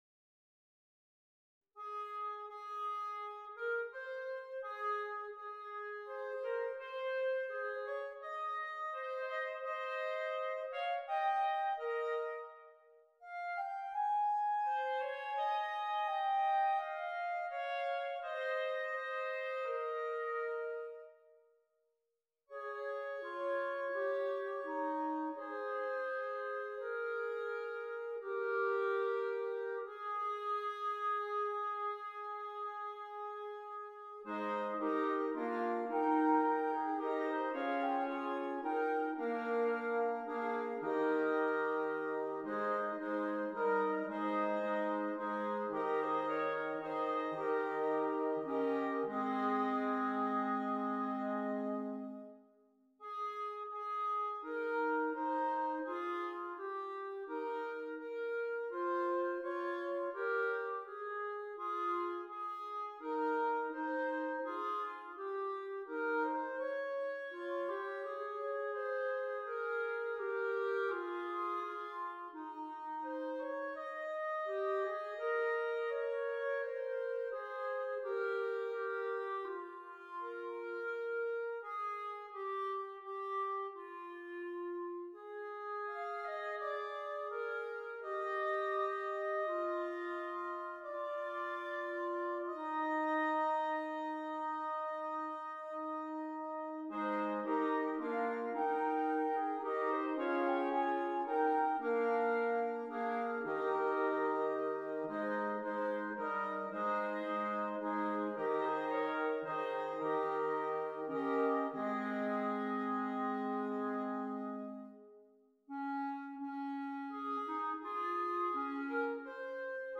5 Clarinets